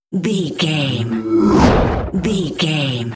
Airy whoosh pass by large
Sound Effects
pass by
sci fi